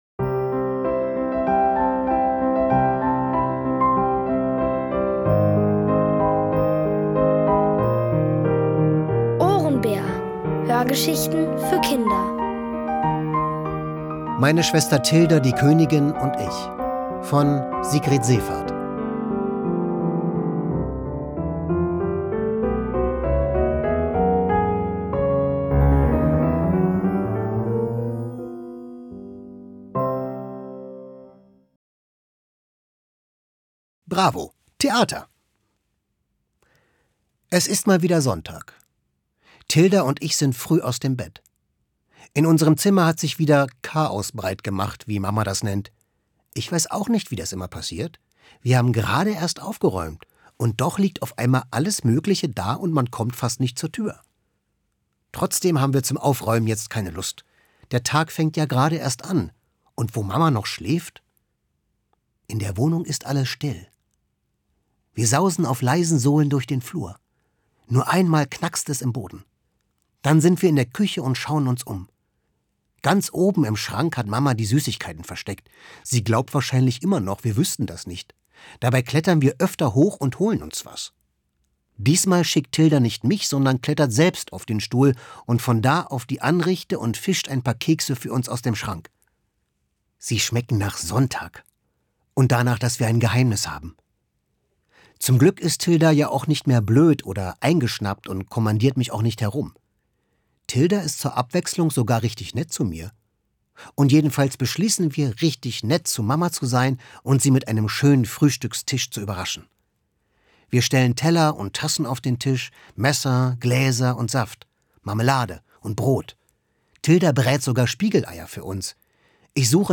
Von Autoren extra für die Reihe geschrieben und von bekannten Schauspielern gelesen.
Es liest: Florian Lukas.